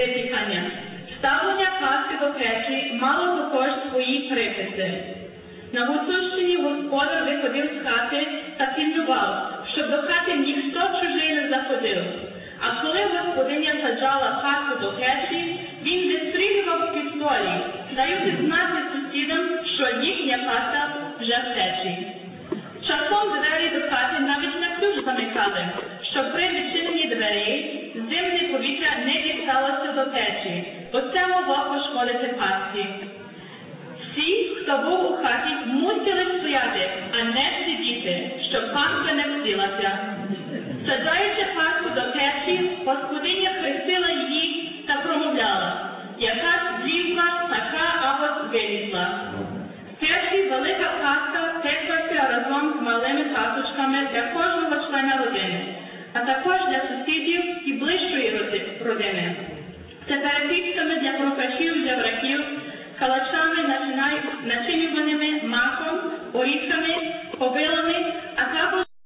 Після вечері та свяченого відбулися виступи дітей та молоді, а також загальна молитва.